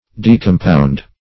Decompound \De`com*pound"\, v. t. [imp. & p. p. Decompounded;